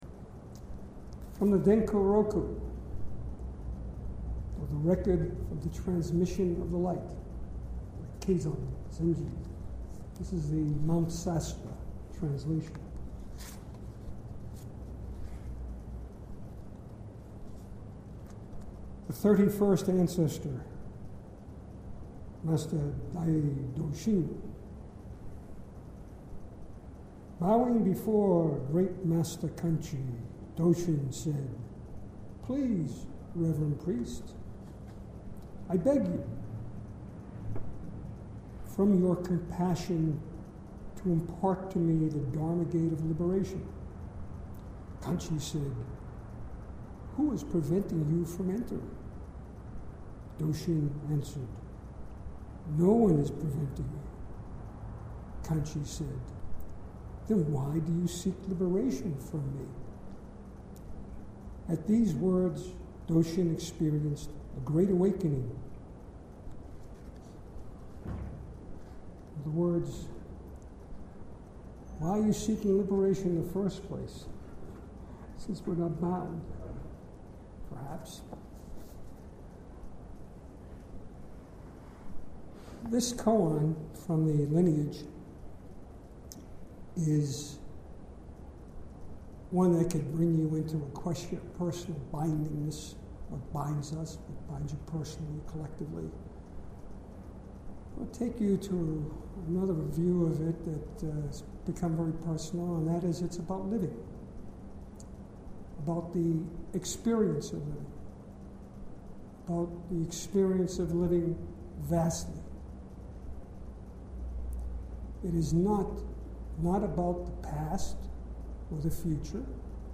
March 2014 Southern Palm Zen Group